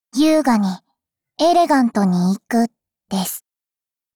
Cv-30105_battlewarcry_11.mp3 （MP3音频文件，总共长4.2秒，码率320 kbps，文件大小：162 KB）